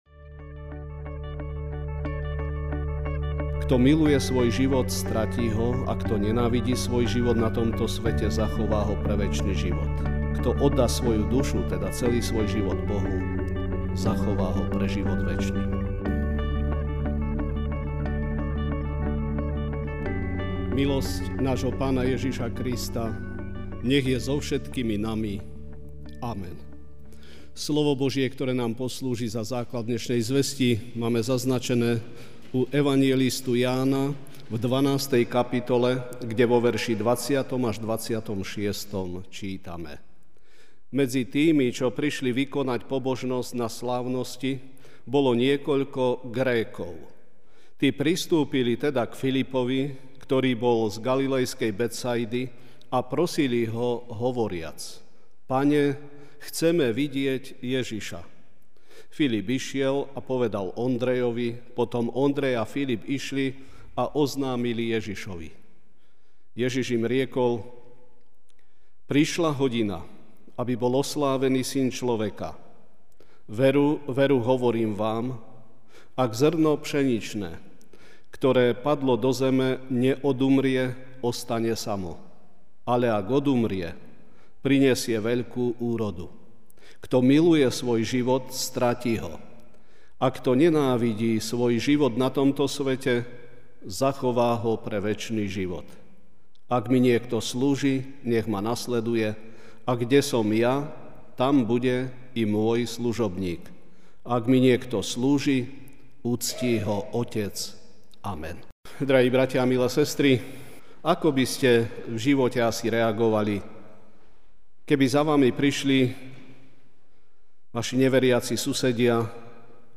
Večerná kázeň: Zo smrti do života (Ján 12,20-26) Medzi tými, čo prišli vykonať pobožnosť na slávnosti, bolo niekoľko Grékov.